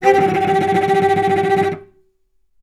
vc_trm-G4-mf.aif